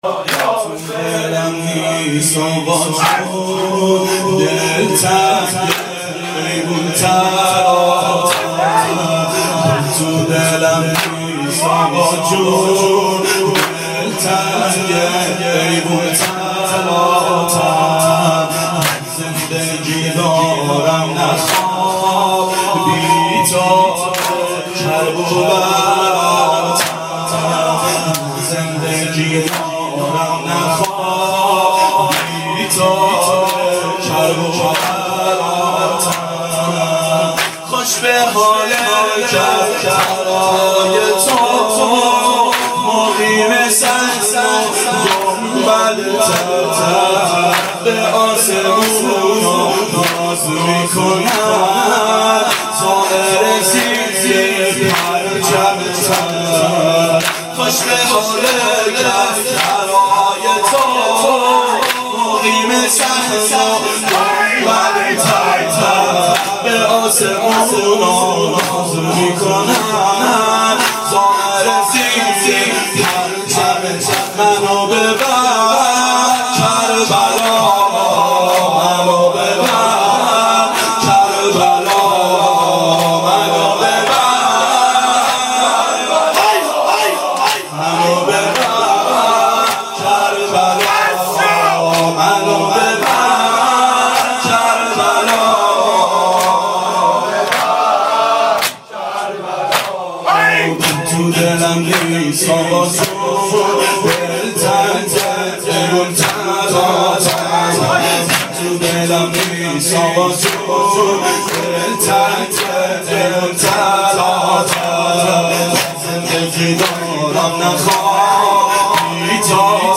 ظهر اربعین سال 1390 محفل شیفتگان حضرت رقیه سلام الله علیها